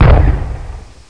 feuerwerk_bang.mp3